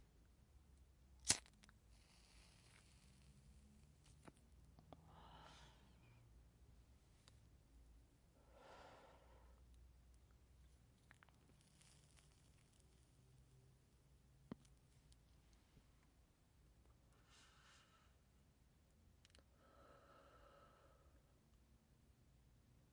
点燃一支雪茄 01
描述：用打火机点燃香烟，吸烟
Tag: 火柴 烟草 火花 照明 气体 吸烟 打火机 点火器 火焰 呼吸入 香烟 裂纹 烧伤 燃烧 烟雾 OWI 吹出